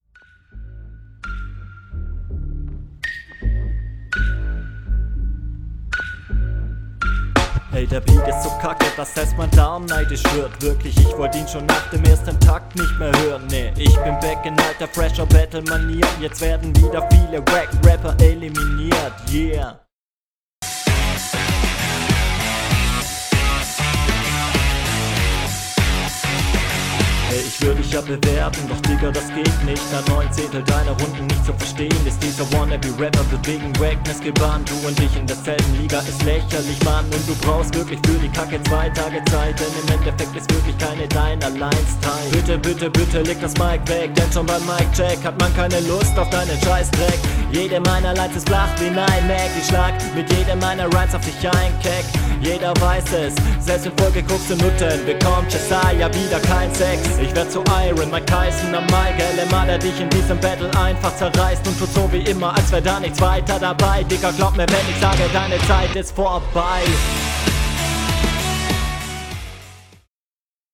Flow: Fast so gut wie der Gegner.
Flow: Flow ist hier auch gut.